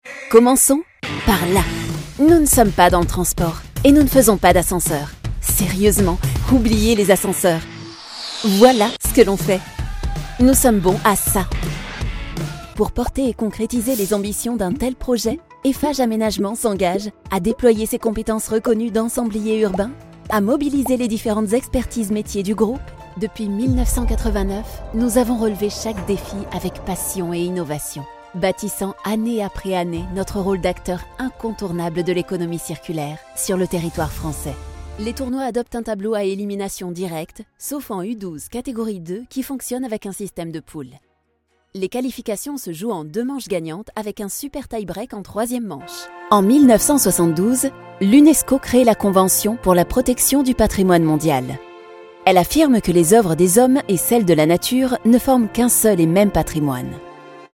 Tief, Verspielt, Vielseitig, Sanft, Corporate
Unternehmensvideo